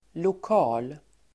Uttal: [lok'a:l]